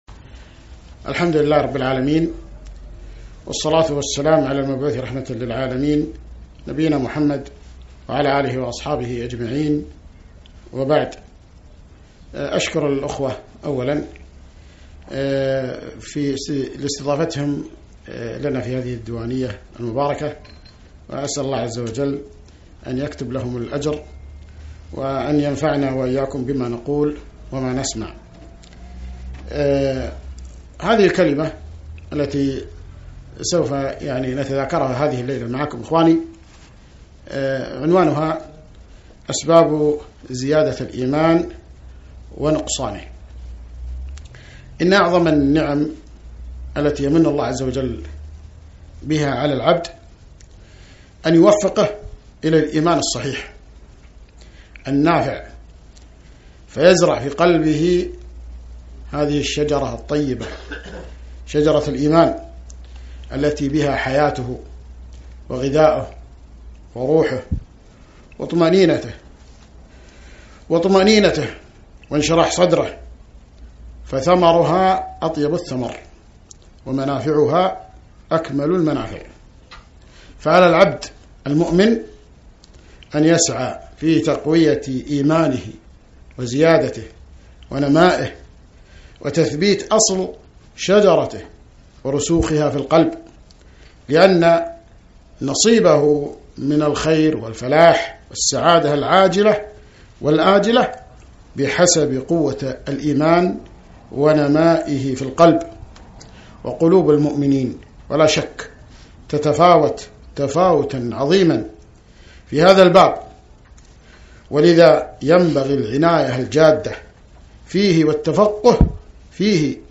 محاضرة - أسباب زيادة الإيمان ونقصانه